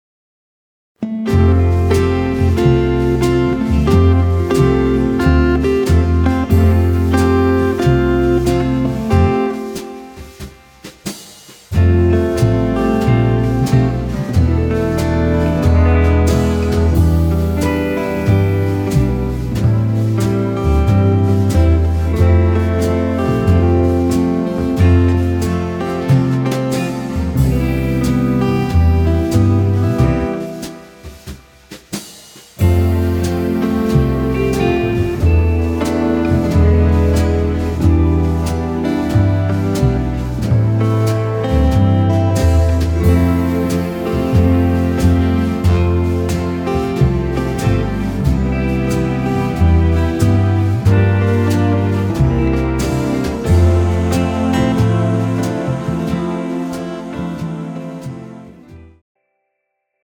slow country swing style
tempo 110 bpm
male backing track